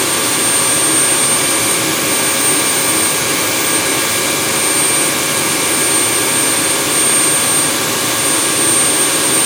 turbo2.wav